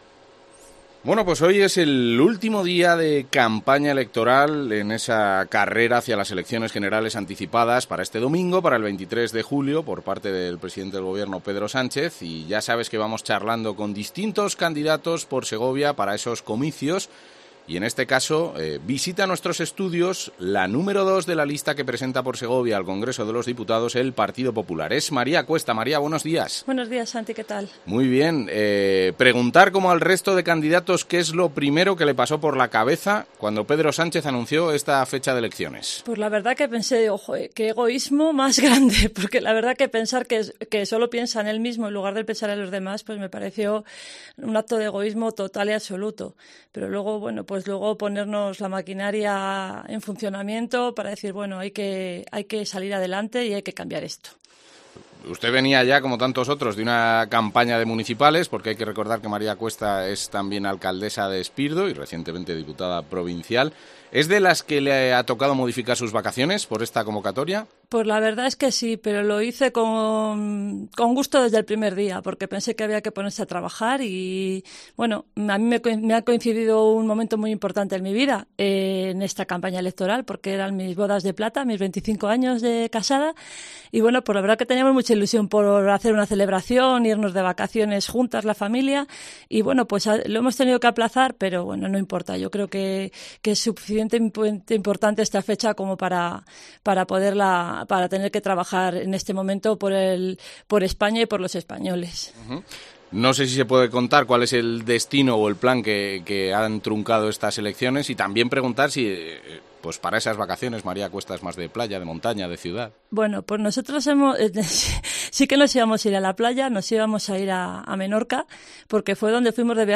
Entrevista con la candidata de cara a las elecciones generales del 23-J